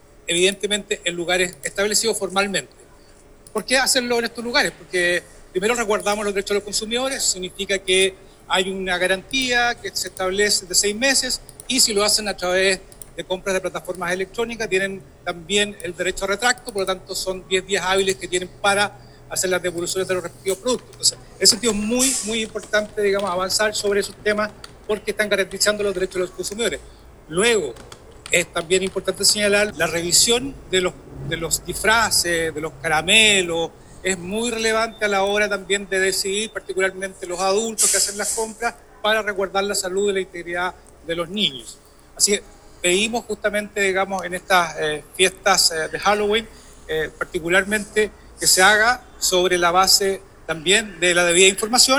En primer lugar, todos los disfraces, accesorios y maquillajes se deben comprar en el comercio formal, tal como señaló el Seremi de Economía de Los Lagos, Luis Cárdenas, quien explicó que todos los productos deben exhibir claramente información en español sobre origen, materiales y advertencias de uso.